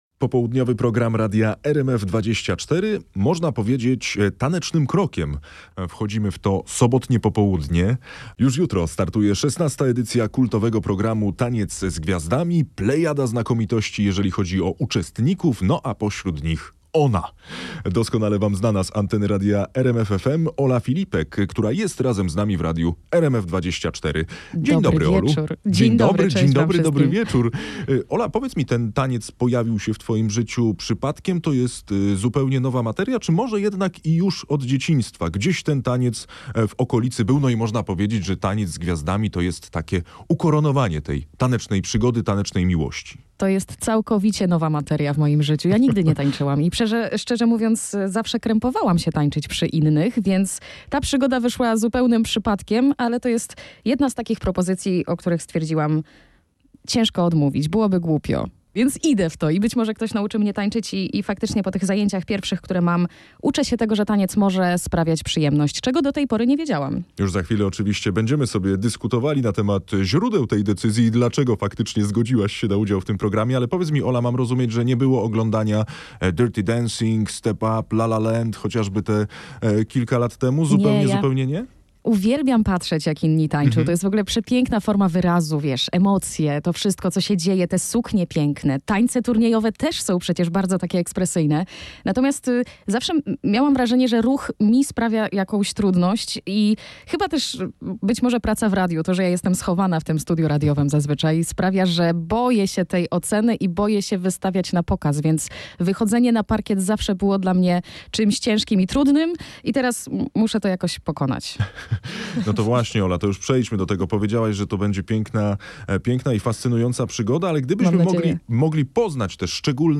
Dziennikarze Radia RMF24 wraz z ekspertami rzeczowo i konkretnie komentują najważniejsze tematy; tłumaczą zawiłości polityki i ekonomii; pomagają zrozumieć, co dzieje się w Polsce i na świecie.